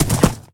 Minecraft Version Minecraft Version snapshot Latest Release | Latest Snapshot snapshot / assets / minecraft / sounds / mob / horse / gallop1.ogg Compare With Compare With Latest Release | Latest Snapshot
gallop1.ogg